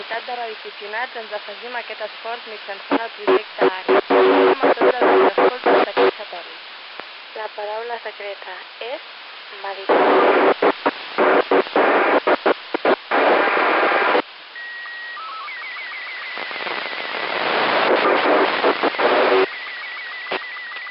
помехи arissat2